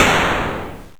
rifle.wav